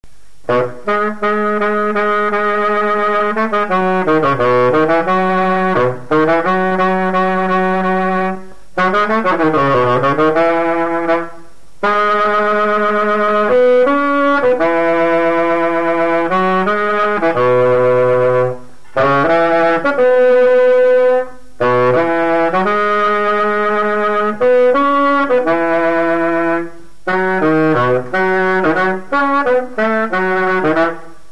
Mémoires et Patrimoines vivants - RaddO est une base de données d'archives iconographiques et sonores.
Marche de cortège de noces
Résumé instrumental
Pièce musicale inédite